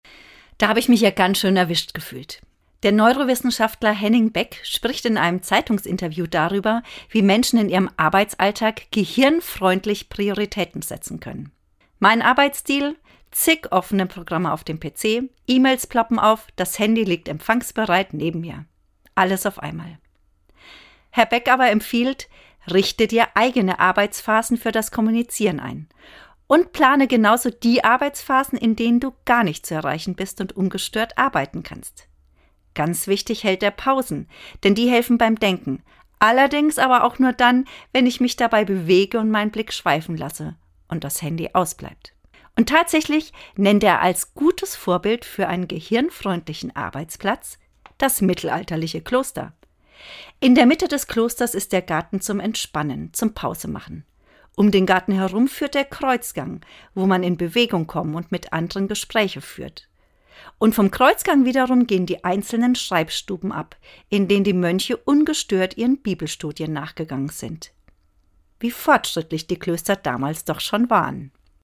Autorin und Sprecherin